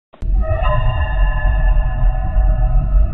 Play, download and share fnaf ambience original sound button!!!!
fnaf-ambience.mp3